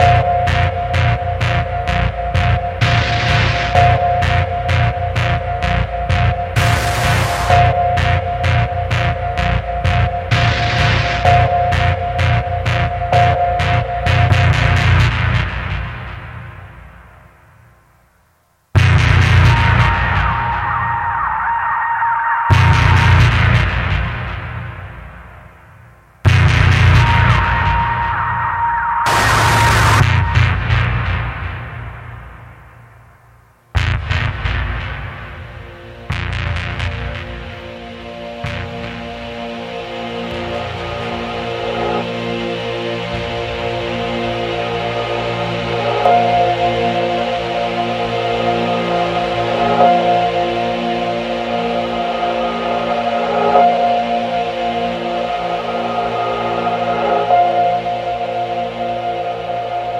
ELECTRONICA / POSTROCK / AMBIENT / EXPERIMENTAL
超自然現象へのオマージュが詰まった実験アンビエントな架空サウンドトラック！
シンセと環境音が交錯する
幽玄な電子音が浮遊する
怪談/オカルト/ホラー文脈とも接続するミステリアスな音像が全編を貫く、深夜のヘッドフォン・リスニング向けアルバム！